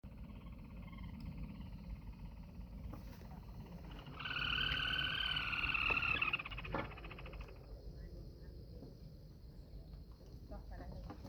Sanã-vermelha (Rufirallus leucopyrrhus)
Nome em Inglês: Red-and-white Crake
Detalhada localização: Eco Área Avellaneda
Condição: Selvagem
Certeza: Observado, Gravado Vocal